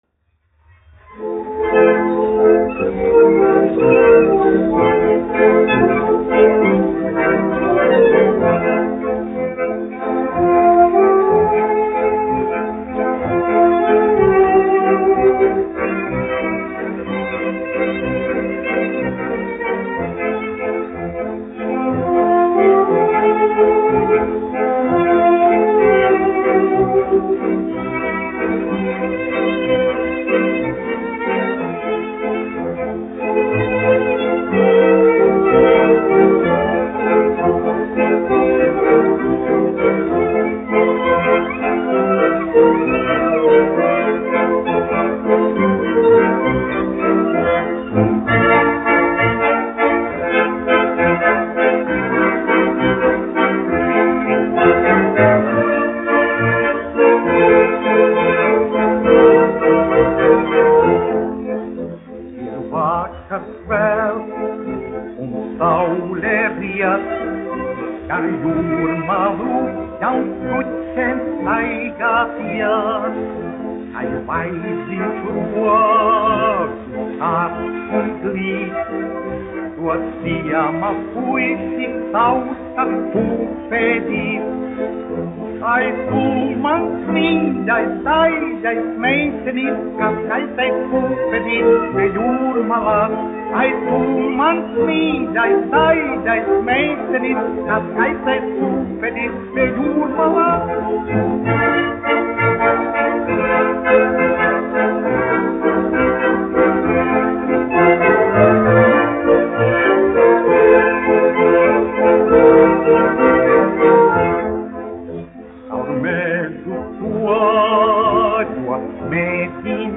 1 skpl. : analogs, 78 apgr/min, mono ; 25 cm
Populārā mūzika
Valši
Latvijas vēsturiskie šellaka skaņuplašu ieraksti (Kolekcija)